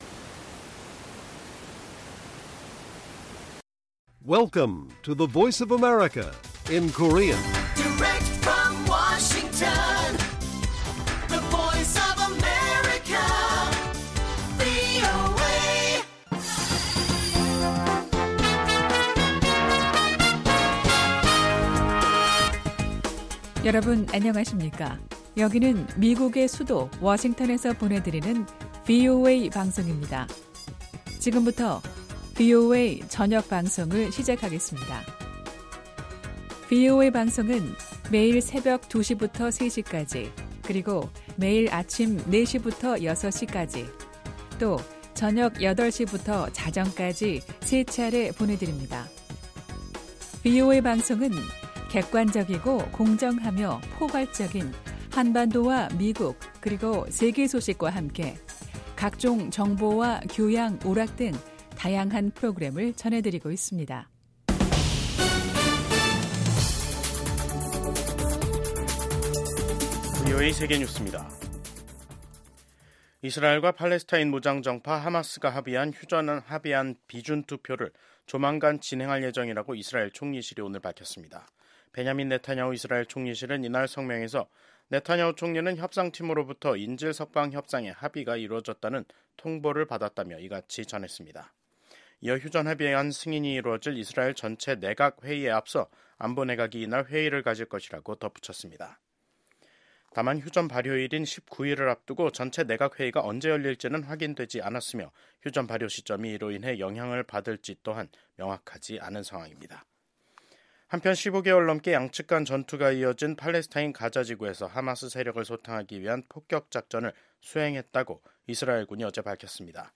VOA 한국어 간판 뉴스 프로그램 '뉴스 투데이', 2025년 1월 17일 1부 방송입니다. 트럼프 2기 행정부 출범과 윤 대통령 탄핵 등 한국의 정치적 혼란으로 인해 단기적으로 미한동맹 간 정치, 경제 모두에서 불확실성이 커질 것으로 보입니다. 미 공화당의 영 김 하원의원은 트럼프 2기 행정부 보좌관들과 한국에 대해 논의했다면서, 비상계엄에 따른 대통령 탄핵 소추, 체포 등이 민주적 절차에 따른 평화적으로 해결해야 한다는 입장에 공감했다고 전했습니다.